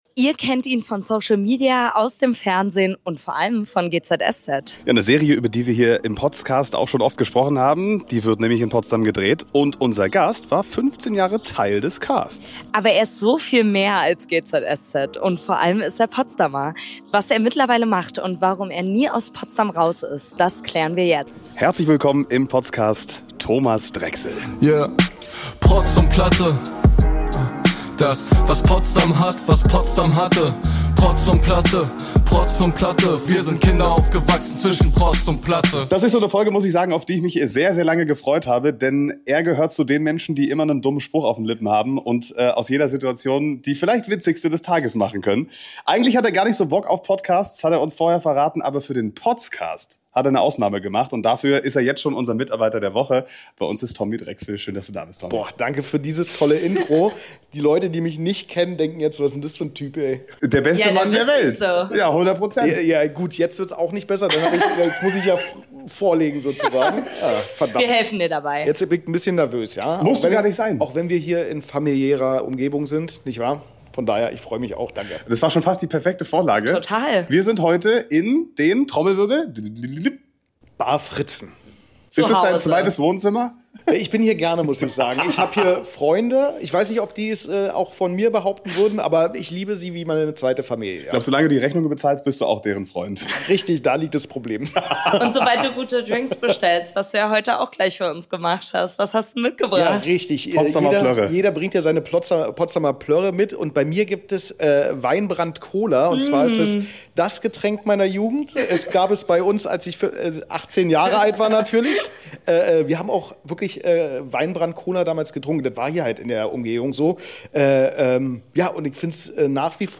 Mittlerweile hat sich in seinem Leben einiges geändert: Wir haben mit Tommy über all das gesprochen, was ihn aktuell beschäftigt. Und das auch noch in seiner Lieblingsbar!